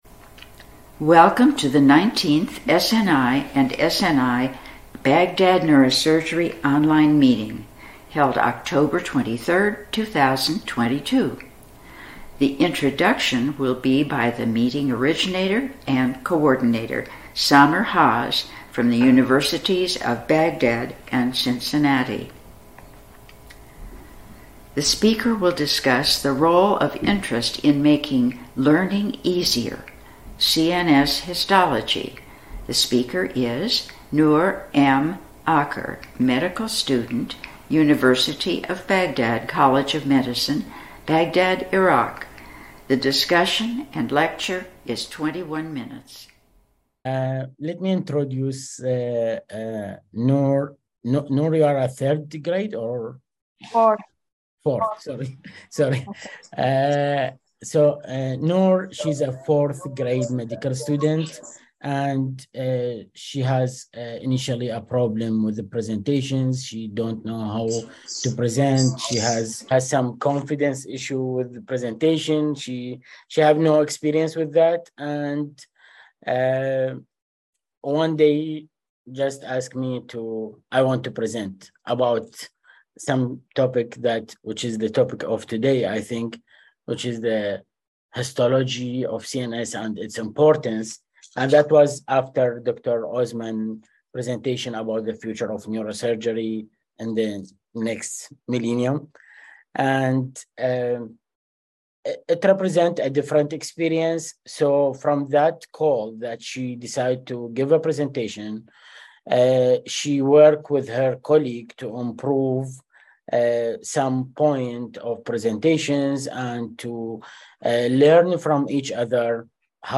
YNSS lecture and discussion